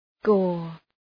Shkrimi fonetik {gɔ:r}